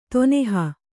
♪ toneha